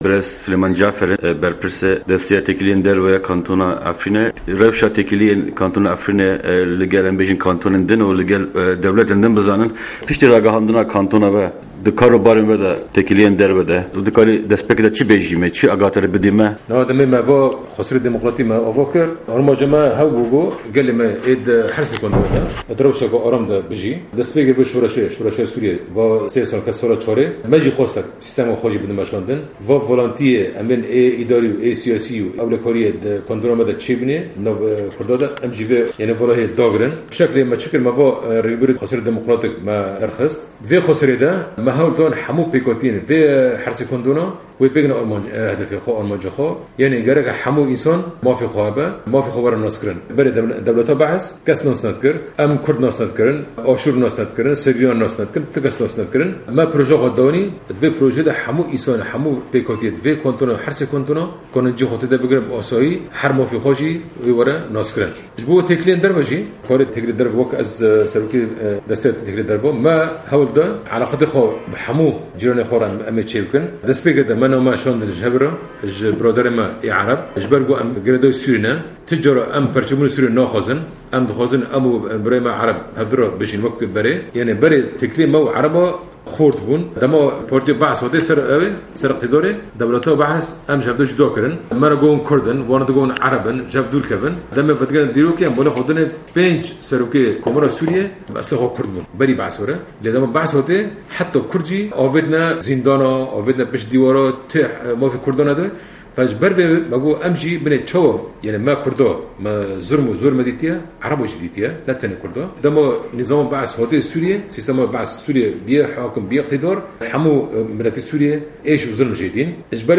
Hevpeyvînek ligel Berpirsê Peywendîyên Derve yê Kantona Efrînê Suleyman Cafer